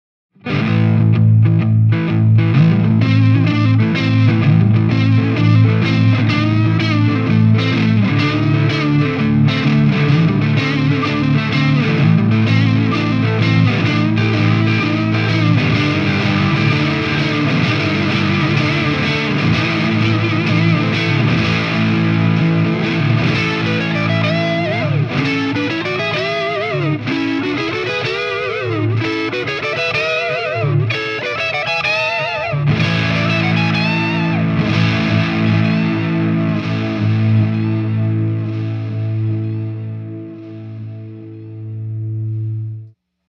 1 Bar Loop: Overdub Control Set To Fade
1440Stereo-Looper-1-Bar-Loop-Overdub-Control-Set-To-Fade.mp3